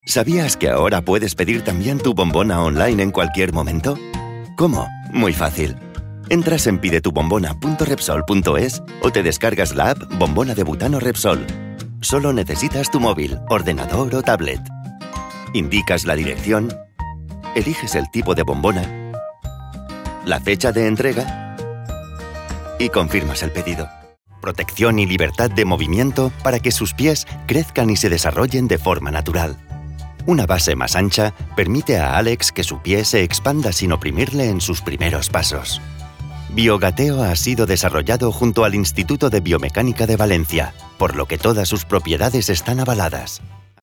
E-learning
Minha voz é dinâmica e jovem, mas também posso modulá-la para tons mais graves e quentes.
Estúdio de gravação caseiro:
Studiobricks: cabine à prova de som e acusticamente adaptada para vozes
micro AT 4040
ConversacionalJovemAmigáveisCorporativoSérioNaturalNeutro